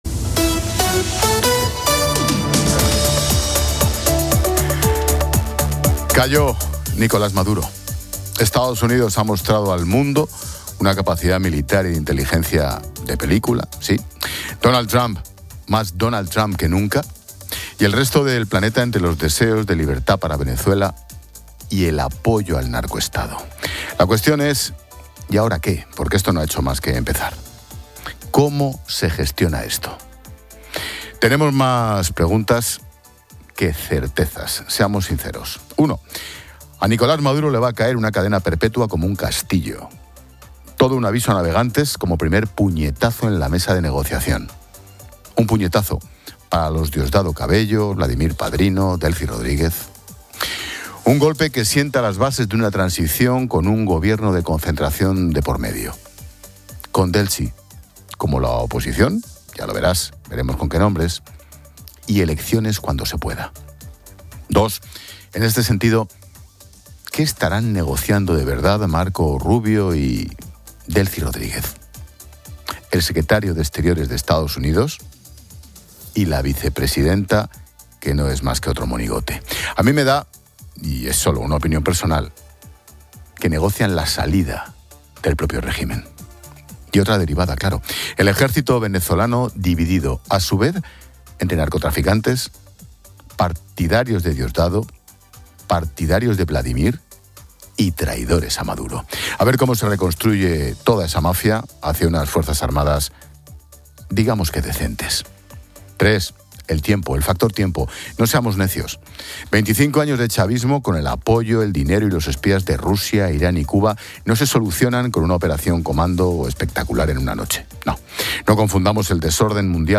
14:00H | 04 ENE 2026 | especial informativo sobre venezuela